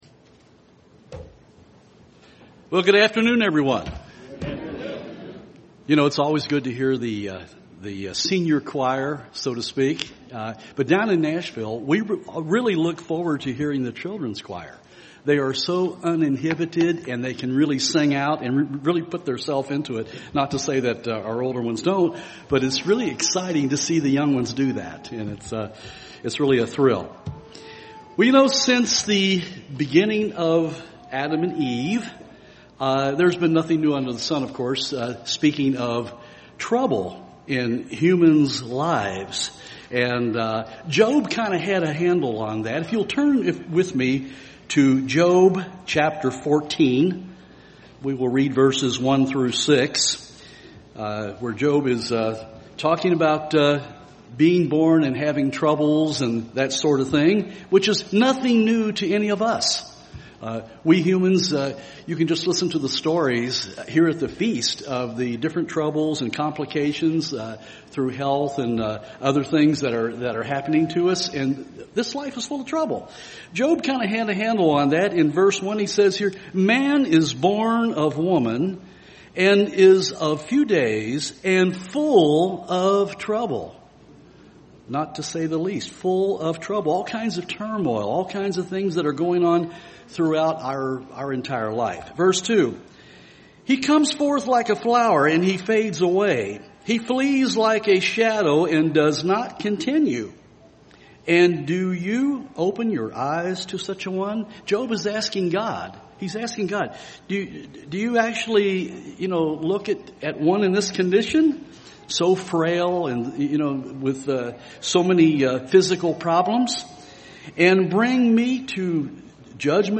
This sermon was given at the Cincinnati, Ohio 2015 Feast site.